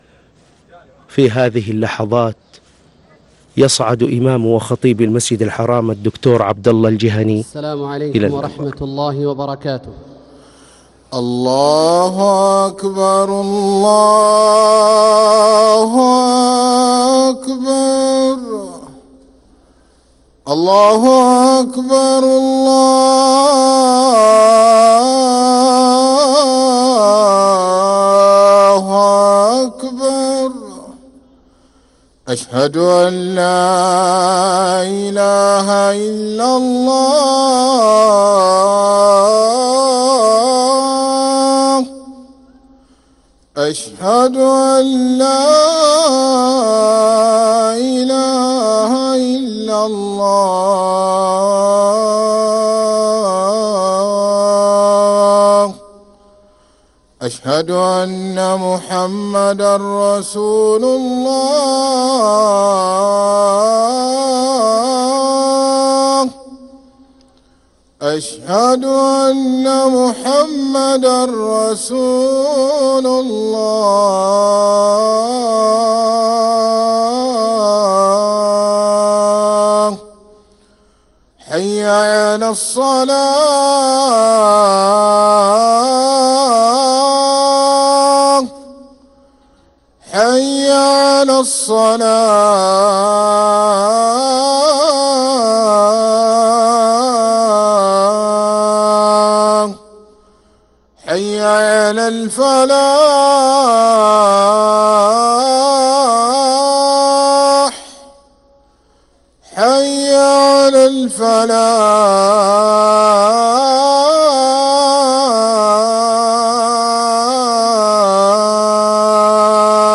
أذان الجمعة الثاني للمؤذن سعيد فلاته 24 ربيع الأول 1446هـ > ١٤٤٦ 🕋 > ركن الأذان 🕋 > المزيد - تلاوات الحرمين